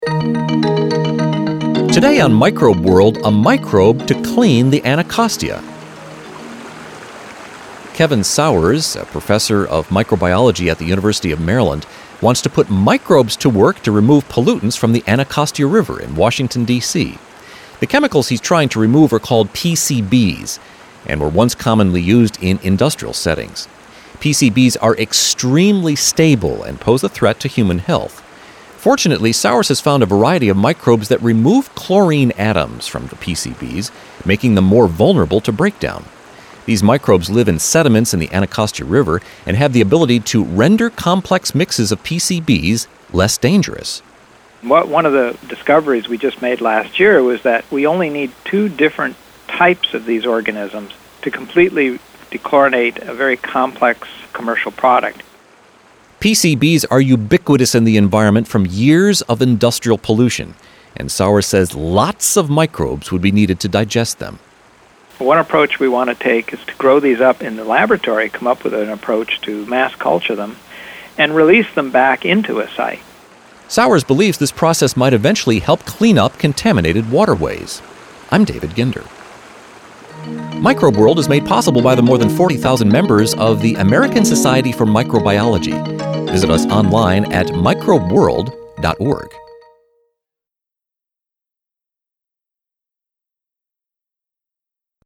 MicrobeWorld interview on PCB Research